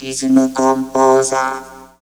68.1 VOCOD.wav